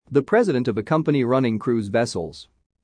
以下、設問１）〜４）の不正解答案文を音読したネイティブ音声を出題しました。
正解の読み上げ音声